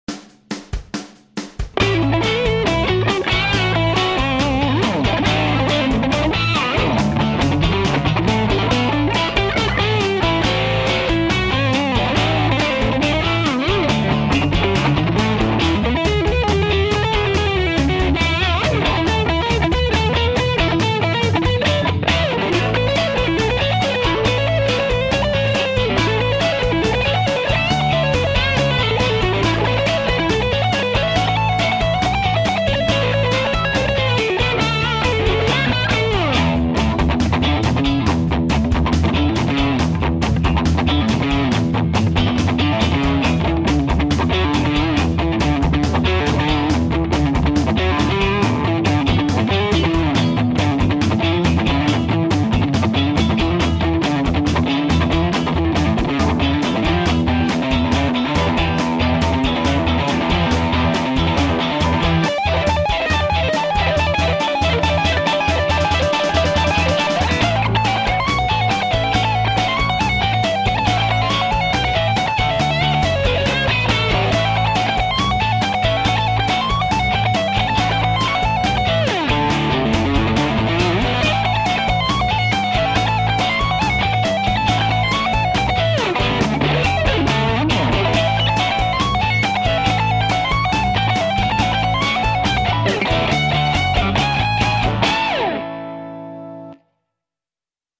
[Rock]